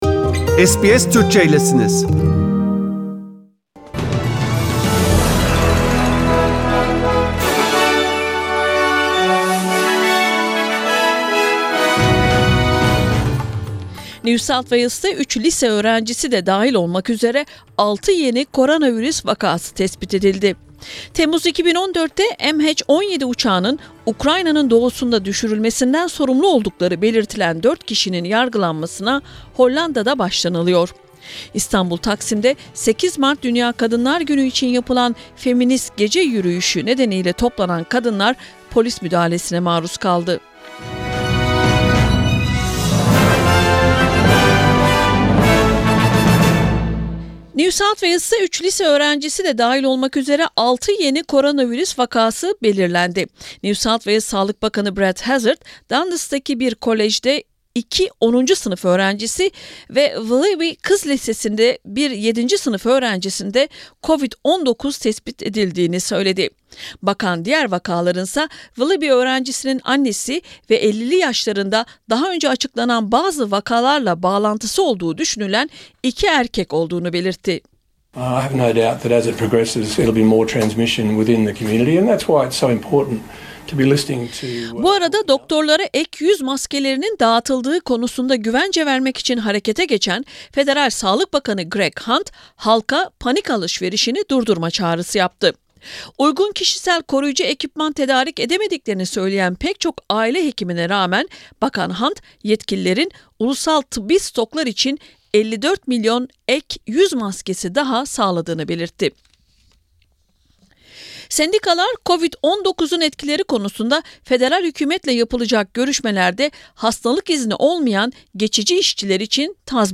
SBS Türkçe Haberler